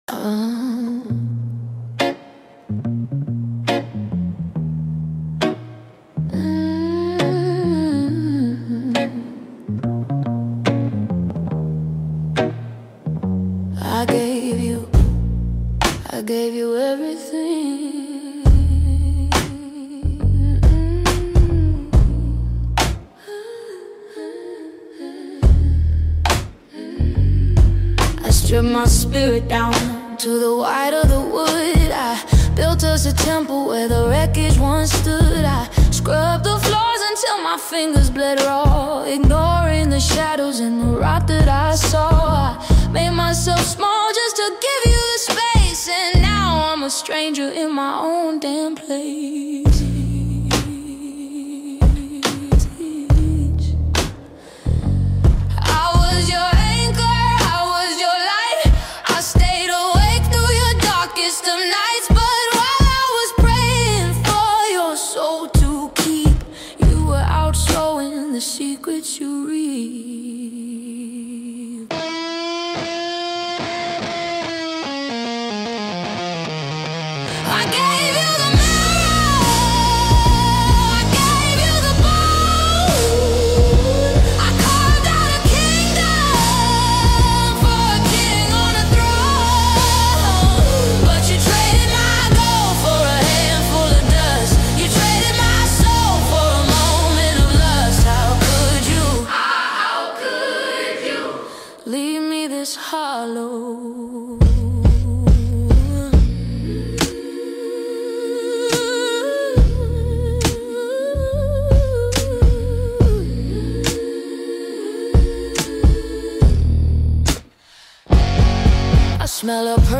There is a heaviness to it, but not in a bad way.